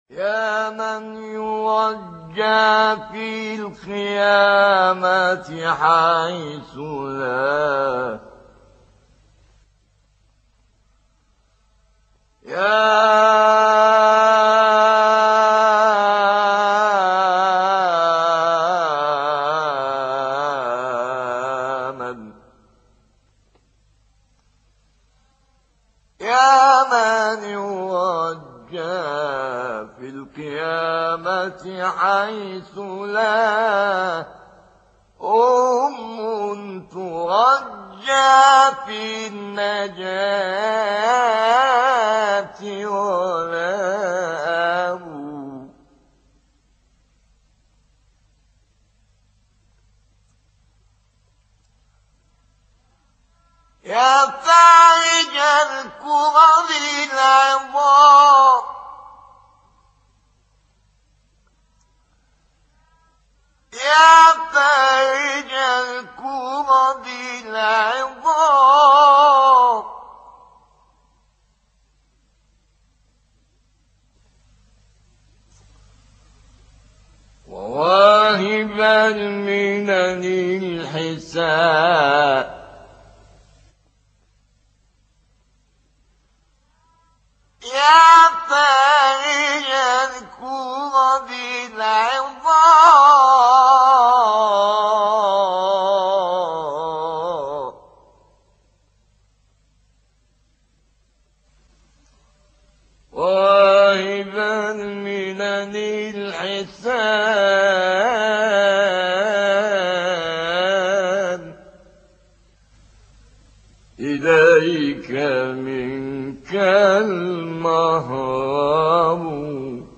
ابتهال شنیدنی از شیخ محمد الفیومی + صوت
کانون خبرنگاران نبأ: شیخ محمد الفیومی مبتهل و موذن مشهور مسجد امام حسین(ع) قاهره است که بیش از سی سال این سمت را بر عهده داشته است.